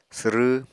[sɨrɨ] n. bugle of horn, usually of yak horn